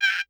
perc_23.wav